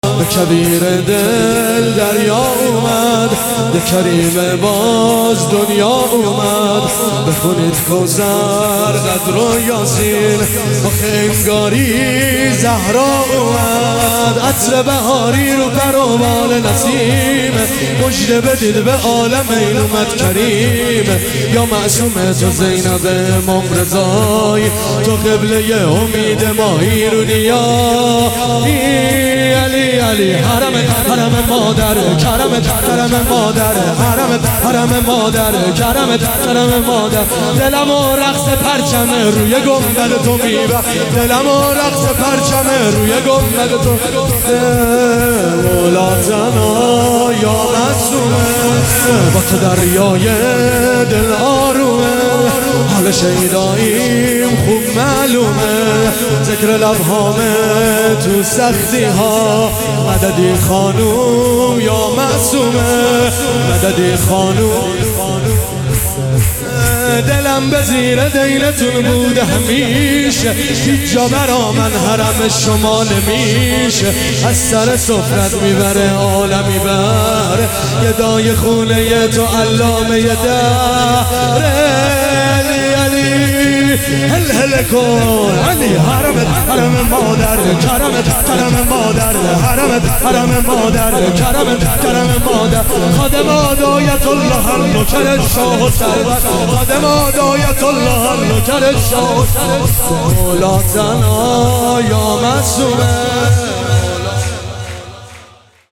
هیئت مکتب البکا مشهد